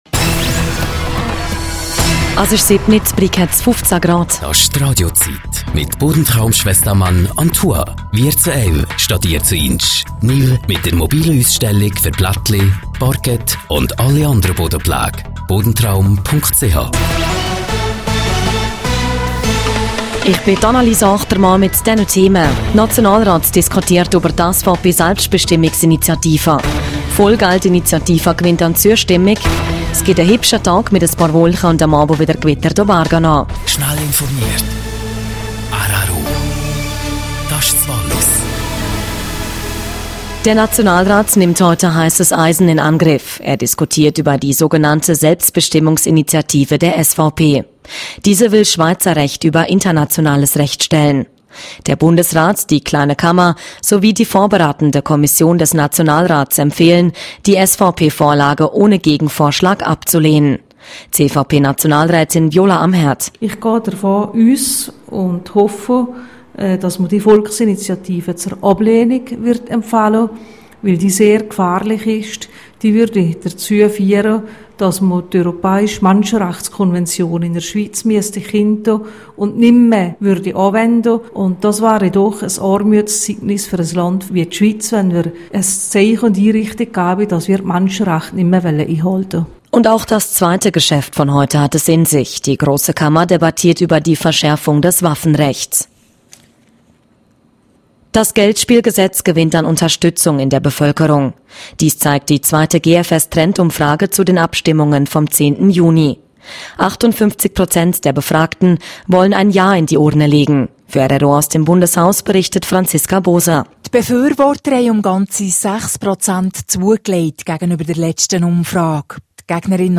07:00 Uhr Nachrichten (4.76MB)